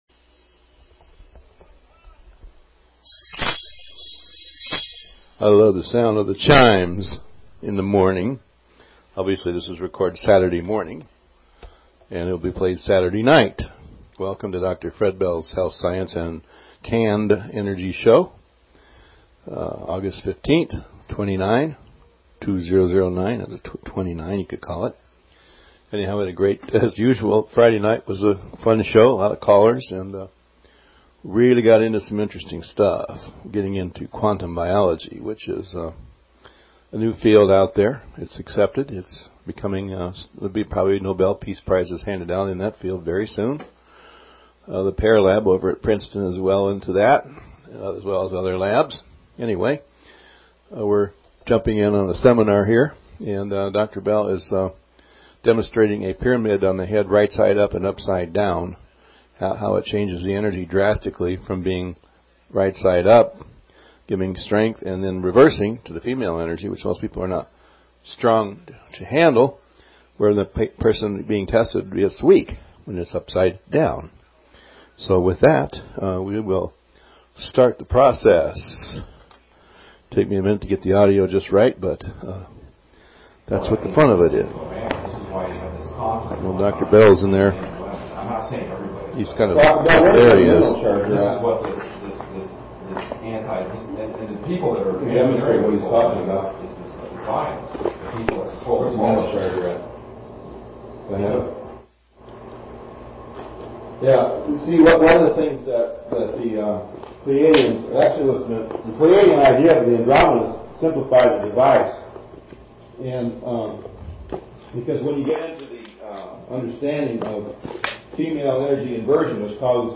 Talk Show Episode, Audio Podcast, Dr_Bells_Health_Science_and_Energy_Show and Courtesy of BBS Radio on , show guests , about , categorized as